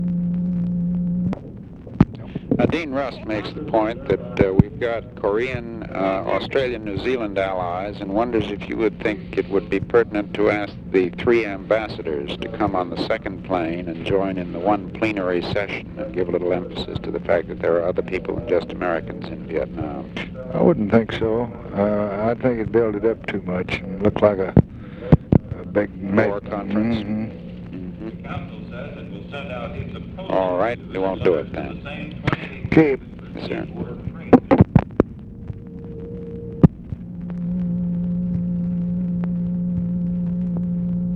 Conversation with OFFICE CONVERSATION, February 5, 1966
Secret White House Tapes